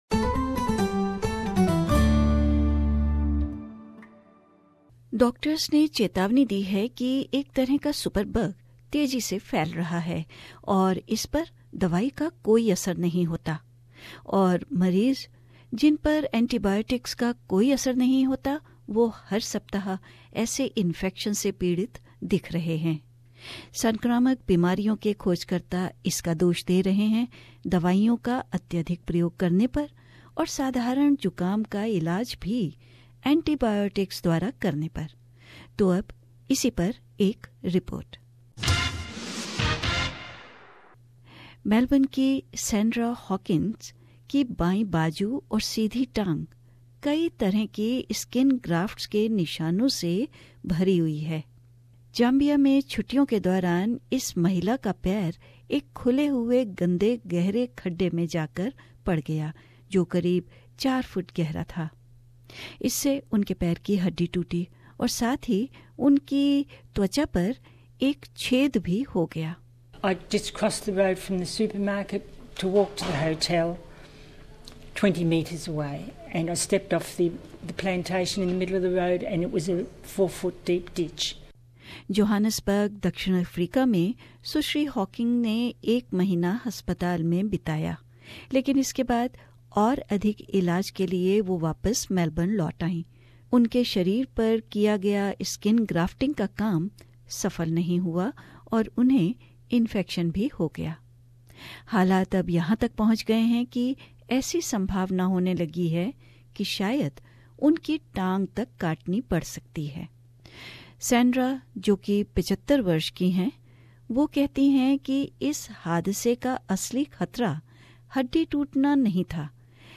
A feature presented…